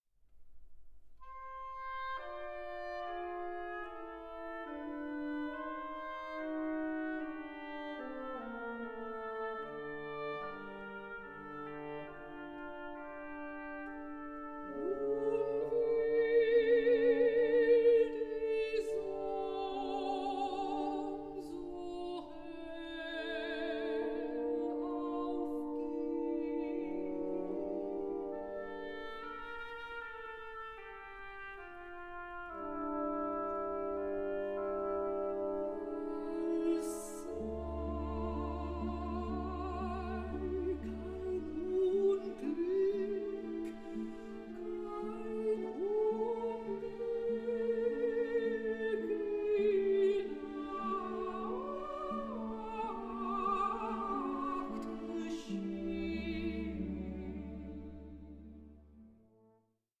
mezzo-soprano
orchestral song cycles